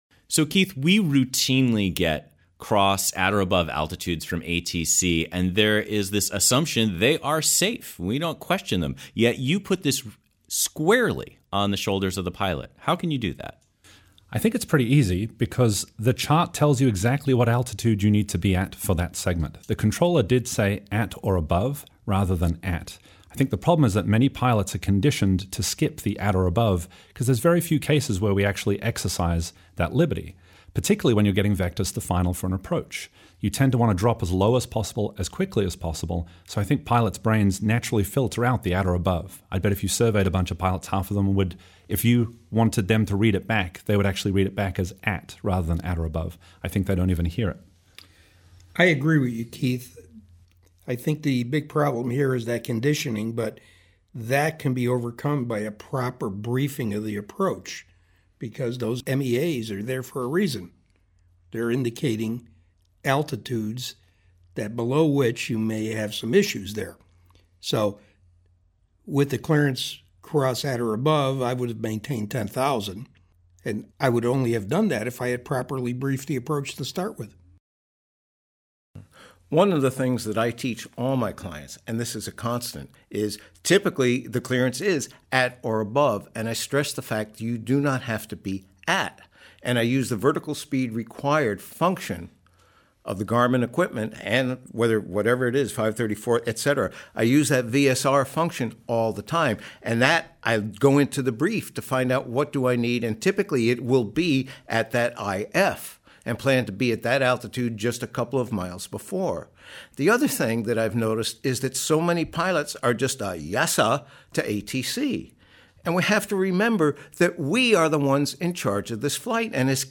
Mixed Messages Over Medford_Roundtable.mp3